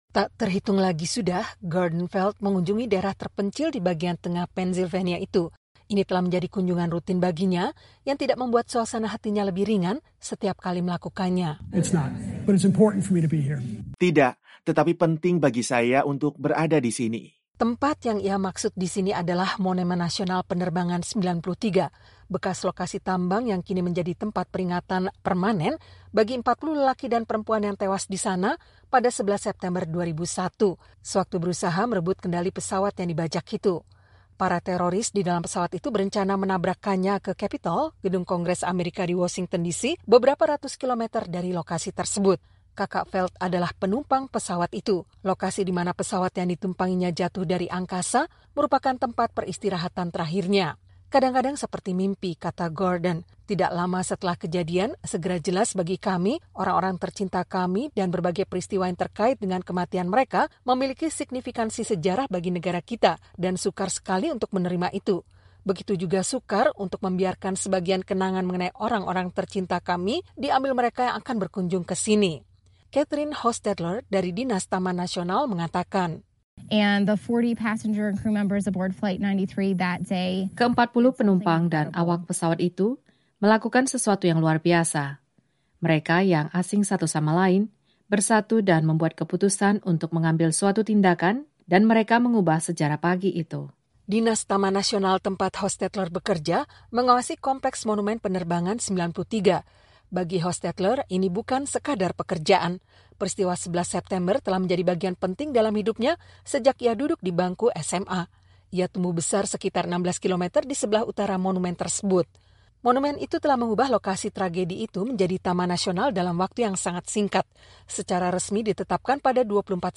Sebuah tambang telantar di dekat Shanksville, Pennsylvania, kini menjadi lokasi monumen terbesar yang terkait dengan serangan teroris 9/11 (11 September). Laporan VOA selengkapnya